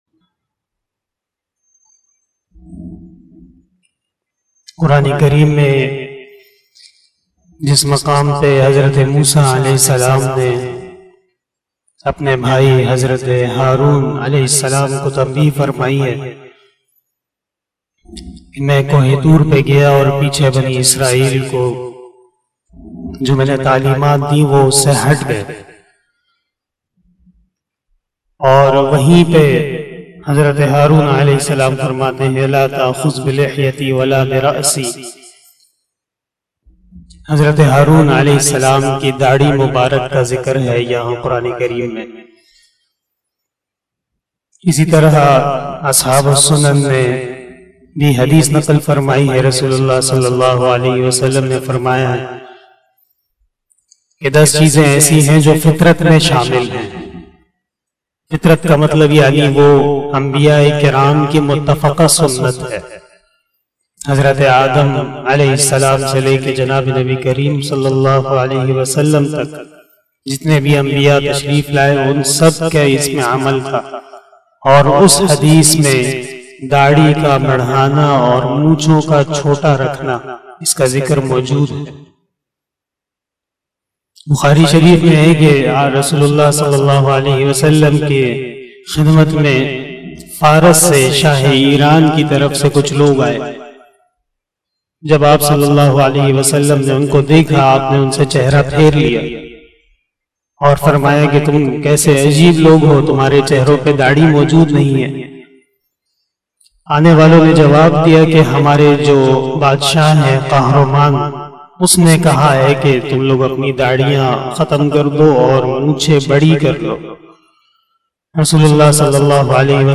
040 After asar Namaz Bayan 10 August 2021 (01 Muharram 1443HJ) Tuesday